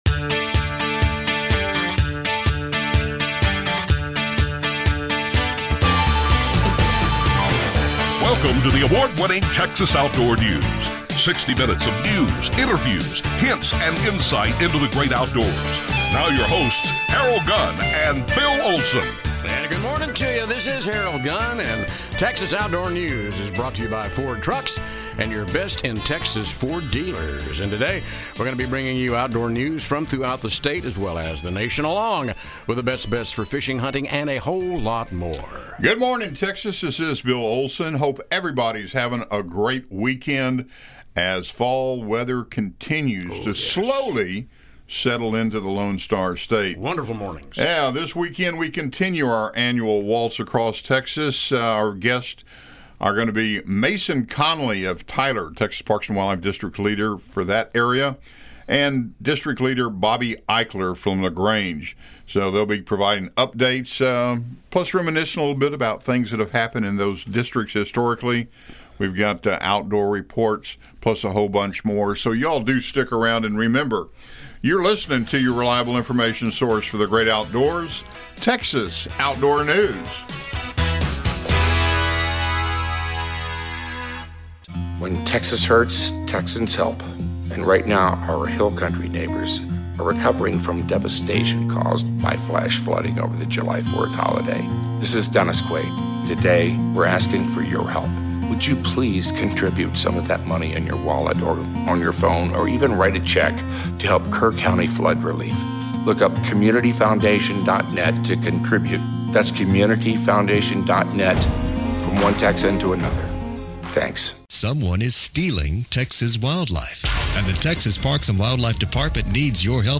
Out annual "Waltz Across Texas" continues featuring all Texas Parks and Wildlife Department District Leaders reporting on current game animal and habitat conditions.